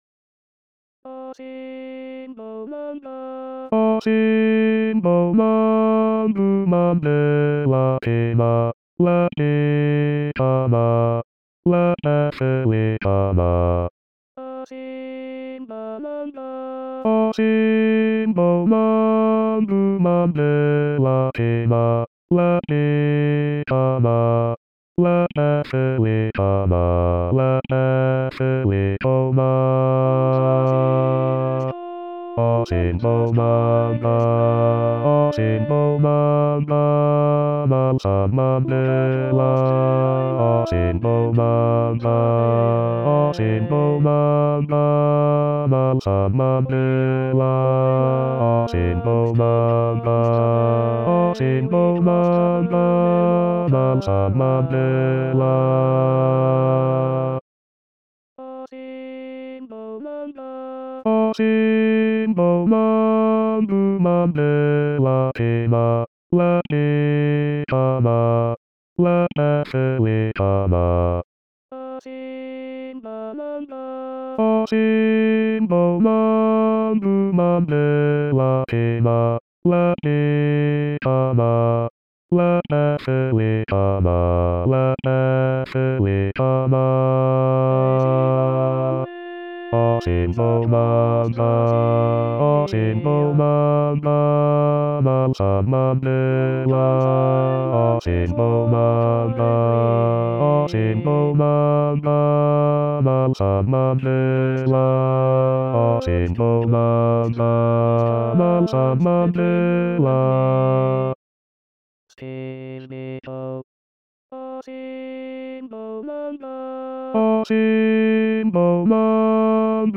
Asimbonanga basses.mp3